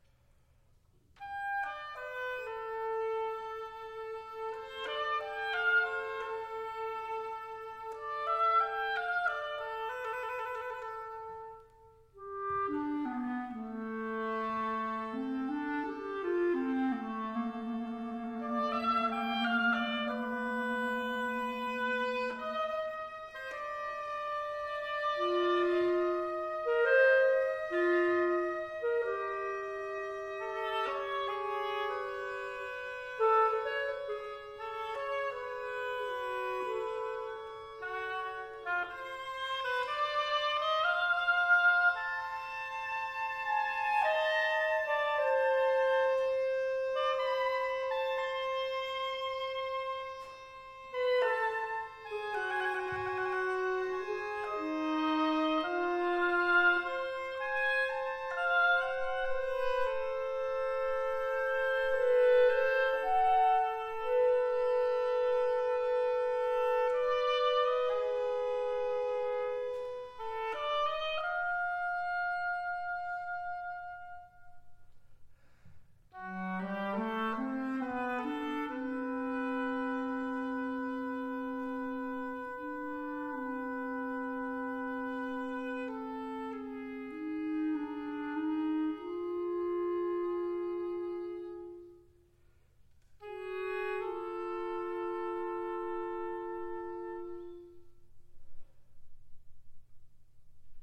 2016.   oboe, clarinet, viola, marimba.
Premiere performance February 6, 2018, at Texas State University Performing Arts Center: